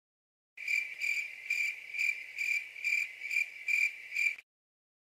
PLAY Akward silece
Play, download and share akward original sound button!!!!
akward-cricket.mp3